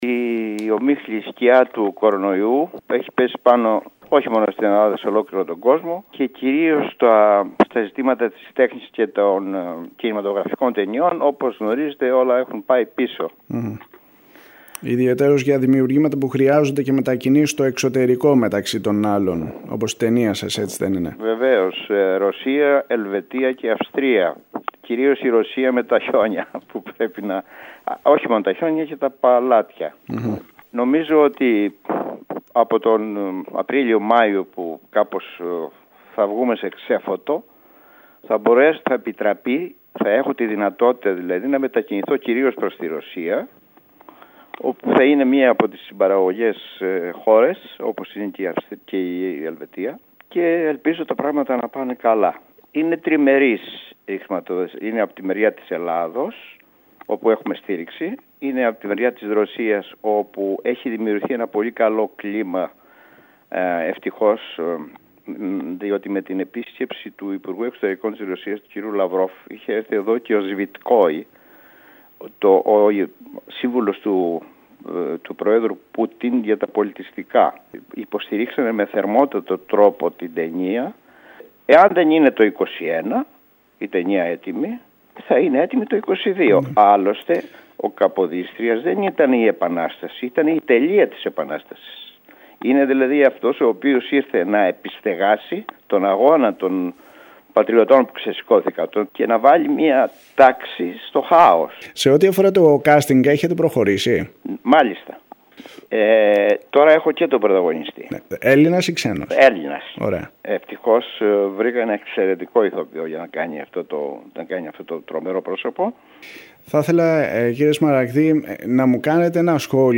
Με αφορμή την επέτειο, η ΕΡΤ Κέρκυρας μίλησε με τον γνωστό σκηνοθέτη Γιάννη Σμαραγδή, ο οποίος γυρίζει ταινία αφιερωμένη στην προσωπικότητα και το έργο του πρώτου κυβερνήτη.